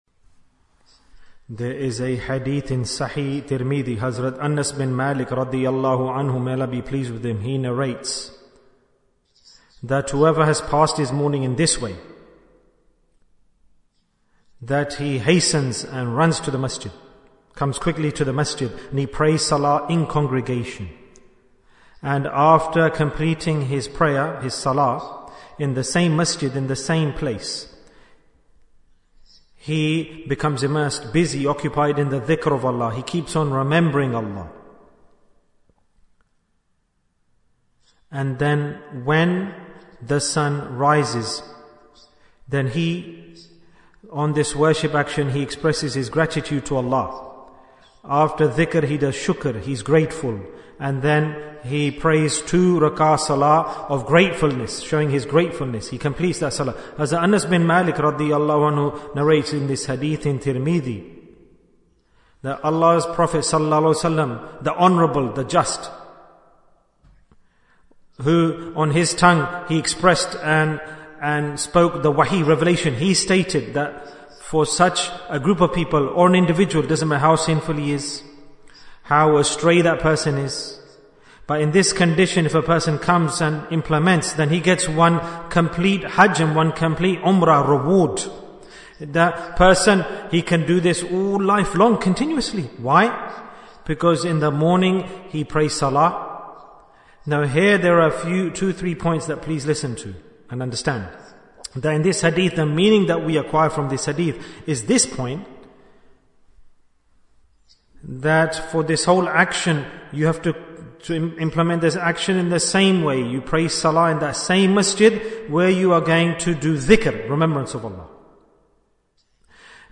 Talk before Dhikr 67 minutes18th September, 2024